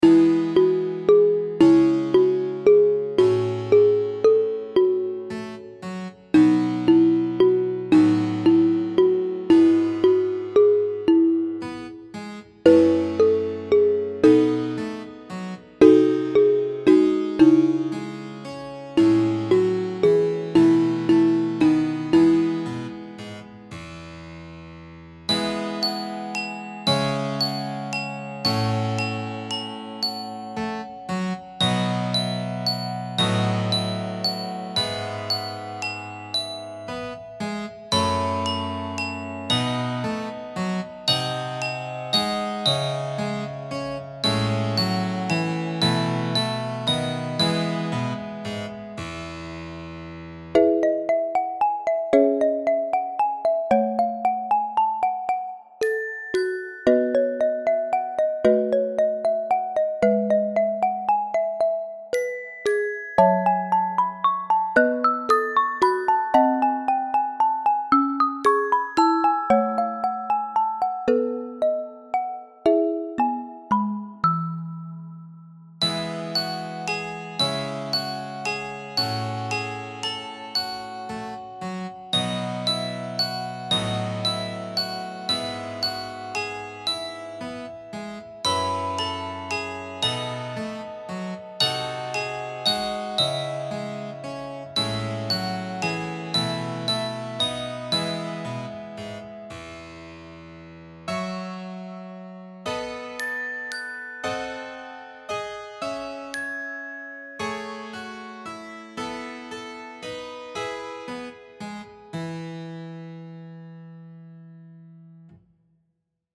Epoque :  Musique d'aujourd'hui
Genre :  ChansonComptine
Enregistrement instrumental